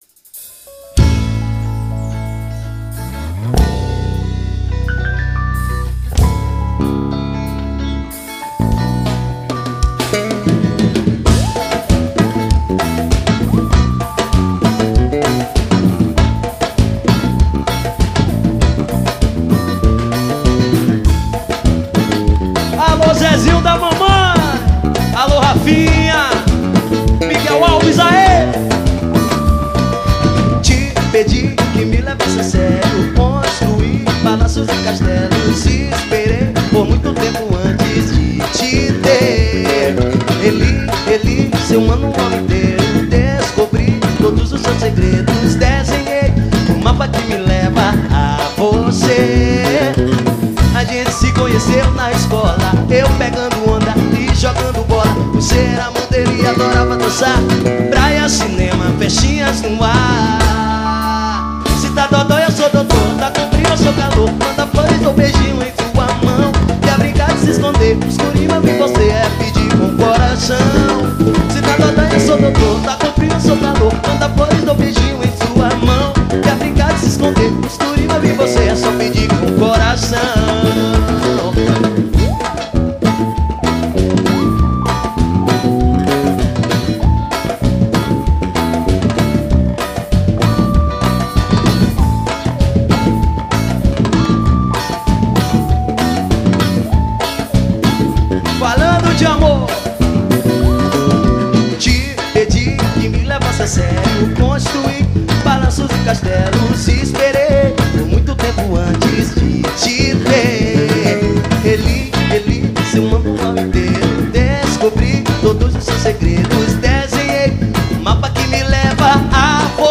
Axé.